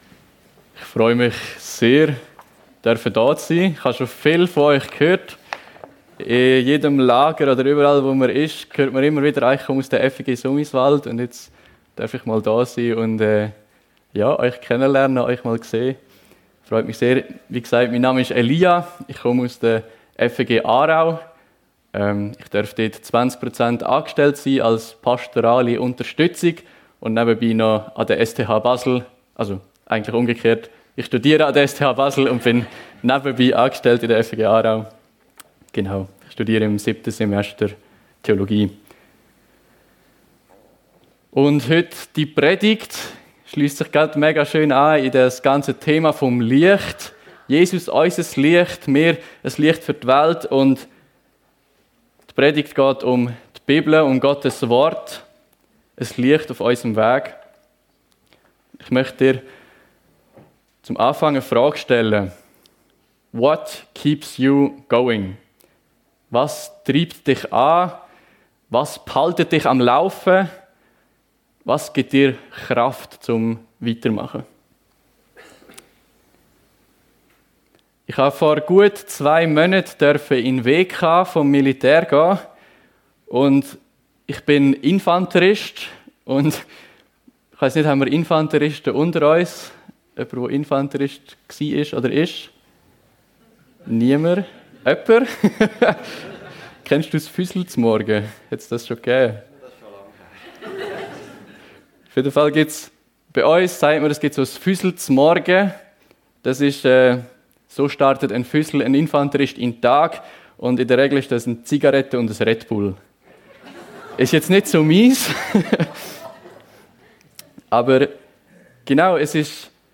Apostelgeschichte 17, 10-12 ~ FEG Sumiswald - Predigten Podcast